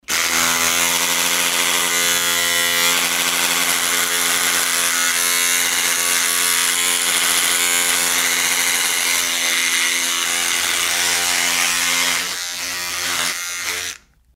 Звуки бритвы